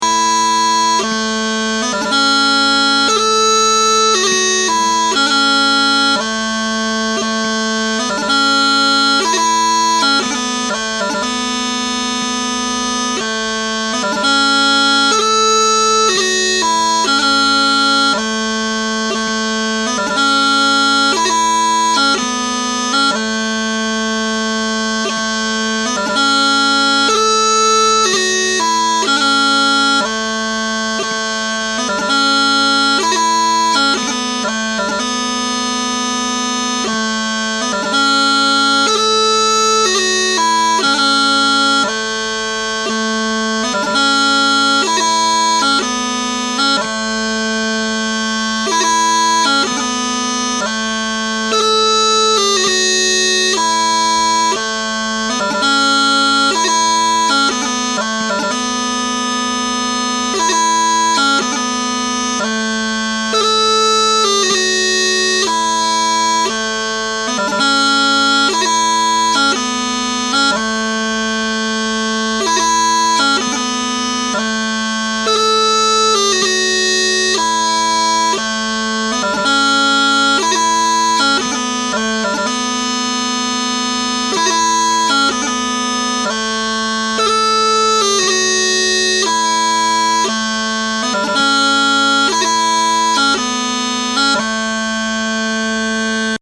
Smallpipes - If you want to play along but my chanter isn't in tune with yours, check out Audacity, which allows you to change the pitch of the audio without changing the speed.
MP3 (played slow)